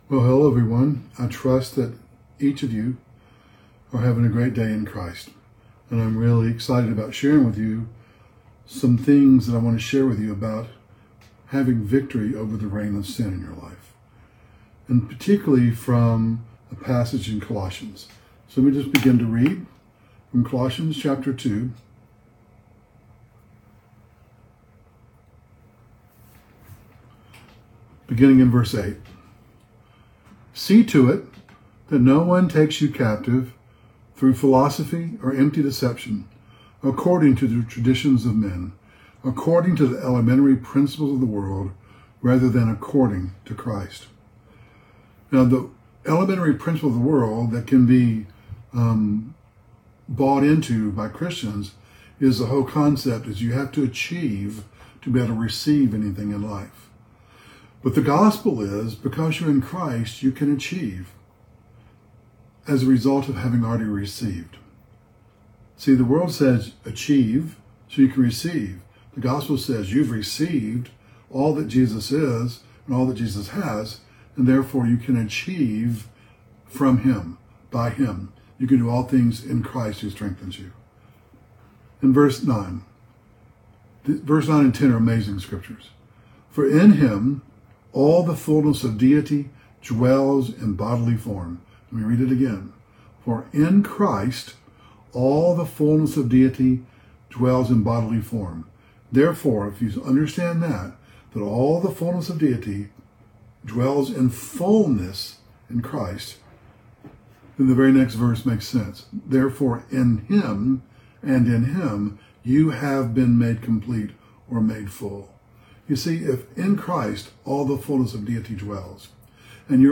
Originally on Facebook Live 6/9/2025